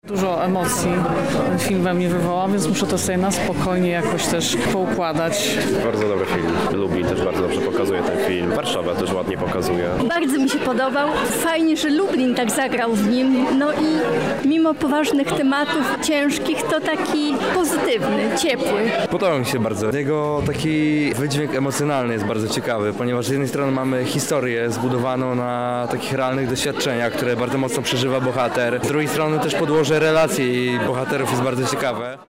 O wrażenia po pokazie „Prawdziwego bólu” zapytaliśmy również jego widzów:
Relacja z pokazu filmu „Prawdziwy ból”